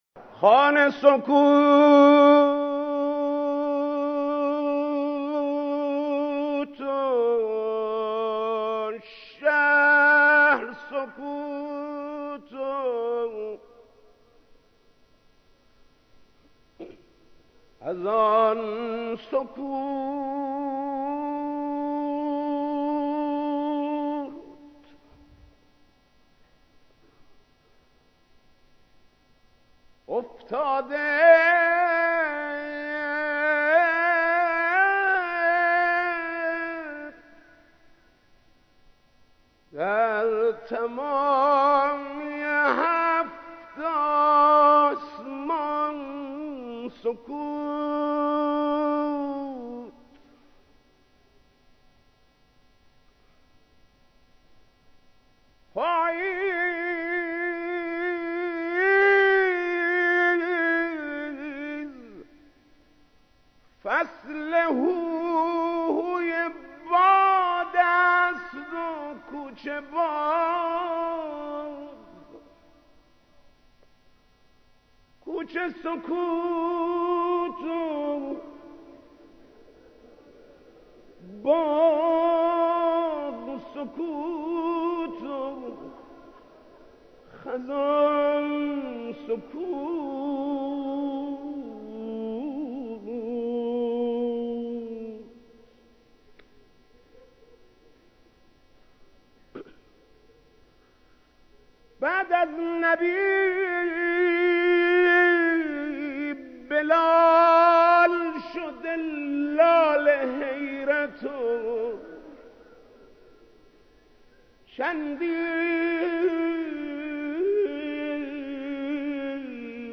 مداحی حاج محمود کریمی/حسینیه امام خمینی(ره)